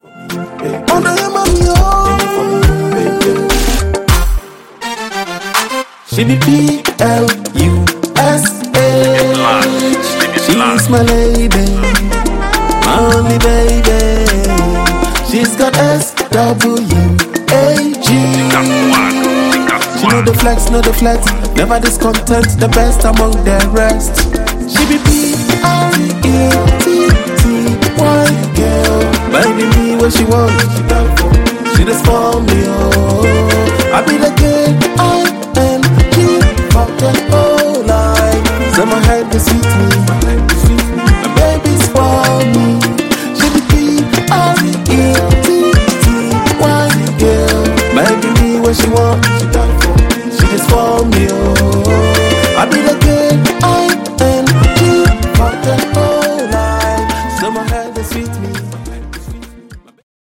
Highlife Music